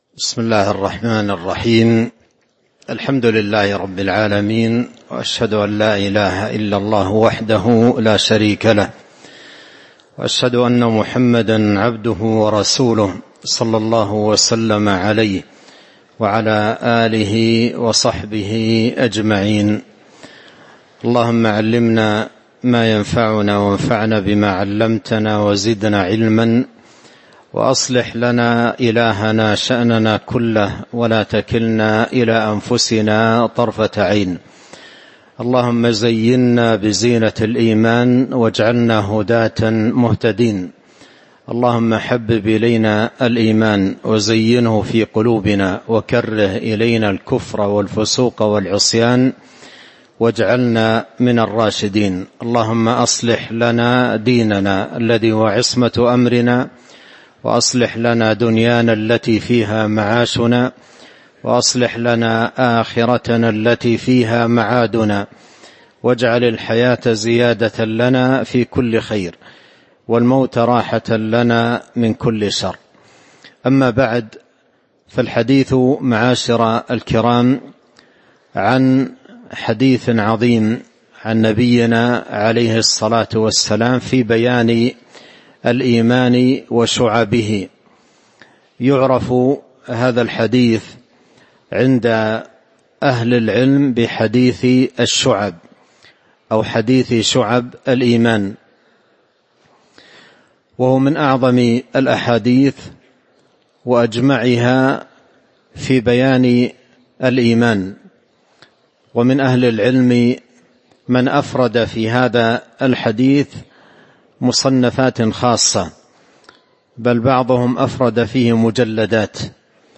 تاريخ النشر ٢٠ جمادى الآخرة ١٤٤٥ هـ المكان: المسجد النبوي الشيخ